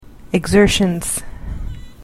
/əɡˈzɝʃən(米国英語), ɪˈgzɜ:ʃʌnz(英国英語)/